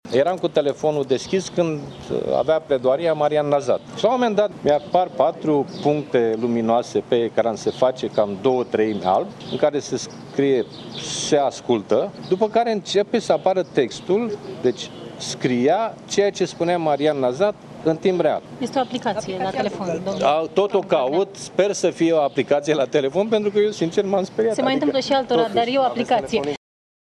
După ce a ieșit de la Înalta Curte de Casație și Justiție, Liviu Dragnea le-a explicat, îngrijorat, jurnaliștilor, că telefonul său este ascultat, însă era vorba despre un asistent virtual încorporat  în sistemul de operare al Google.